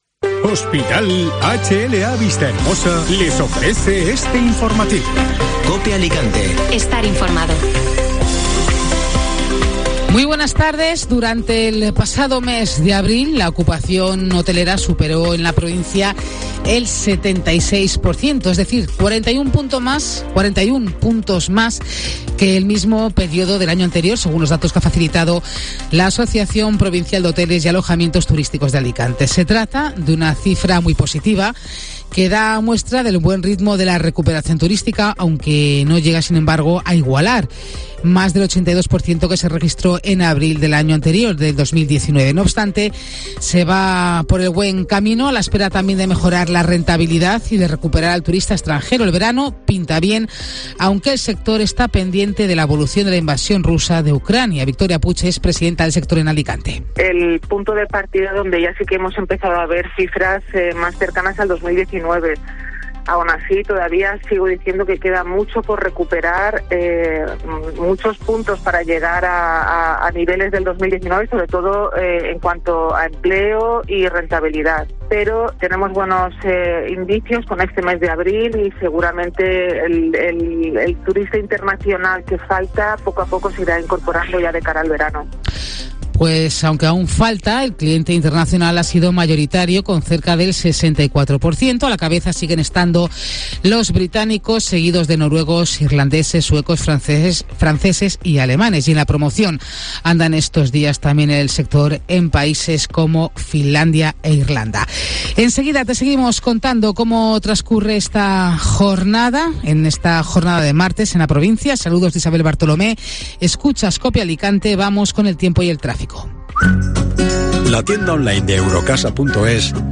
Informativo Mediodía COPE (Martes 10 de mayo)